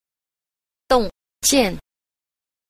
9. 洞見 – dòngjiàn – động kiến (thấy rõ)
Cách đọc: